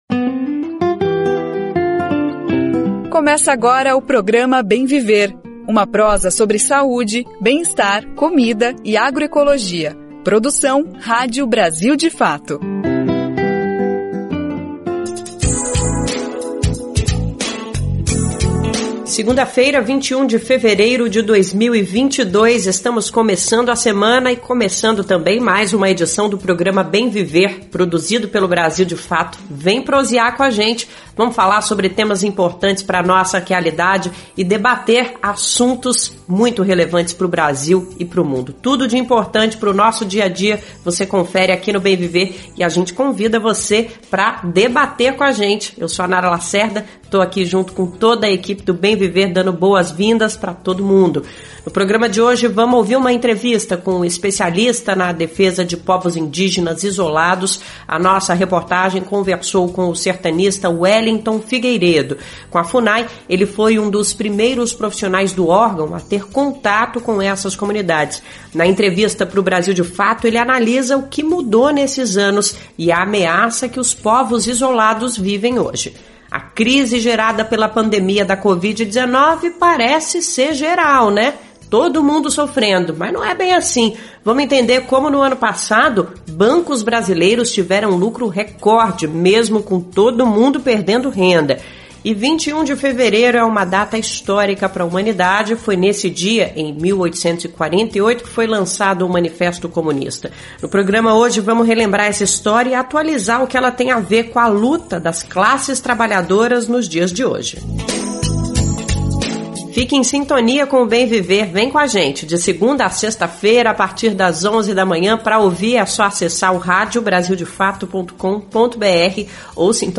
Conversa Bem Viver